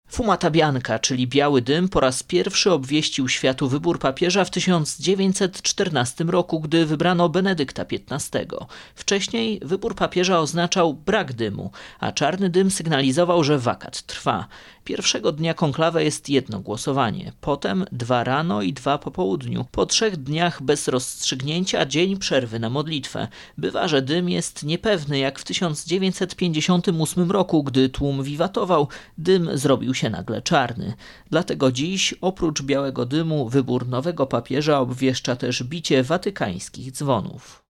AUDIO: Relacje dotyczące konklawe.
Biały dym z Kaplicy Sykstyńskiej to symbol wyboru papieża, nieprzerwanie połączony z konklawe. Relacja